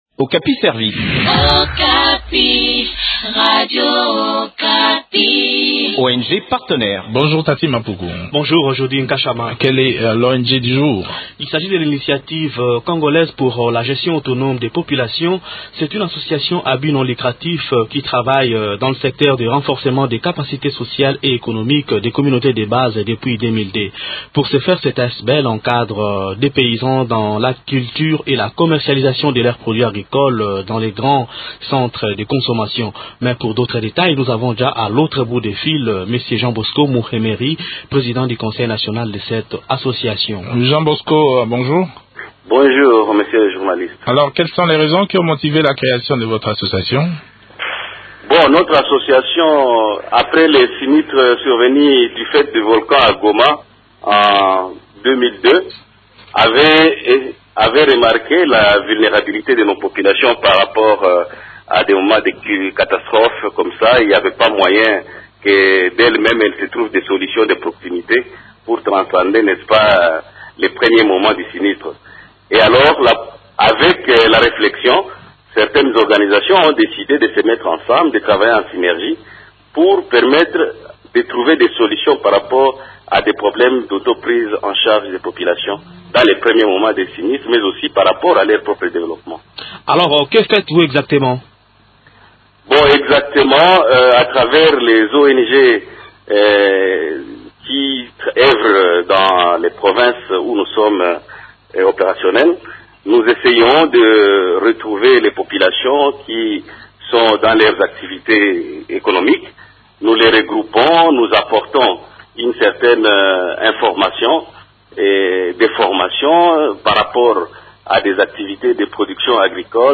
Découvrons en détail les activités de cette ONG dans cet entretien